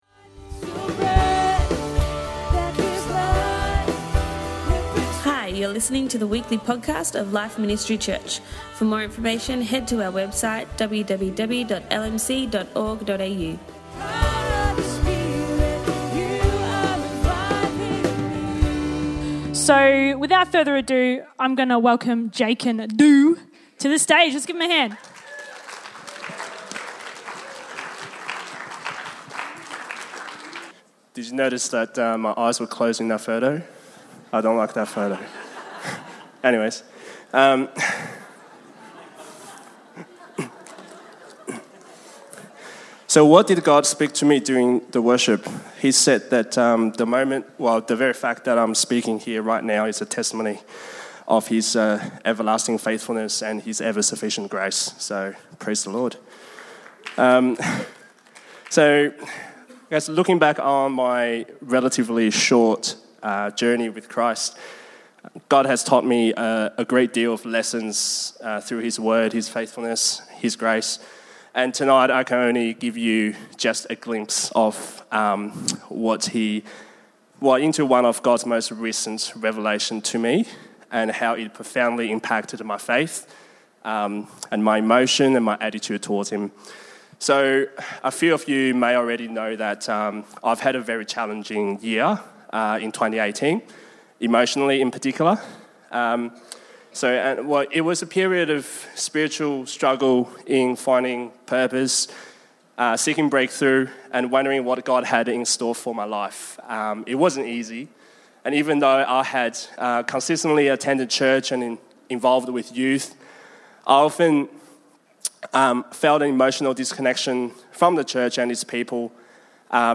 Young Adults Testimony Service
We heard testimonies from a few of our young adults at LMC!